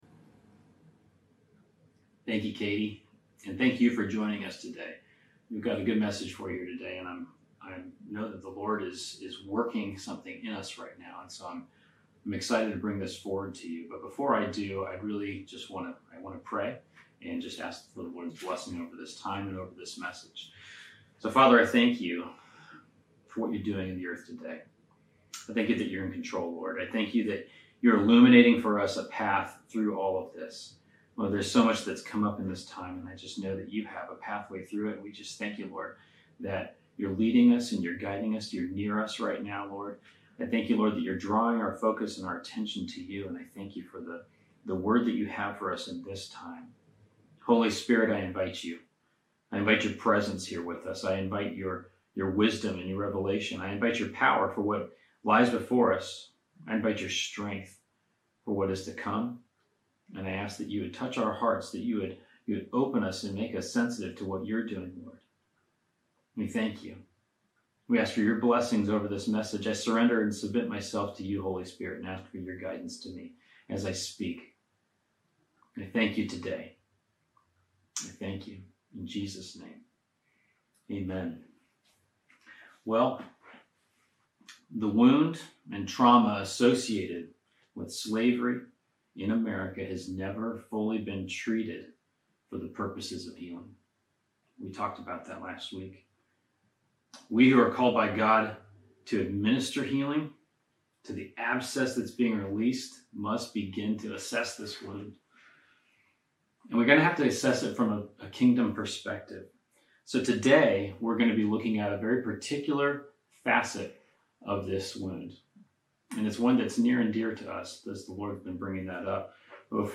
Welcome to Our Father's House online service.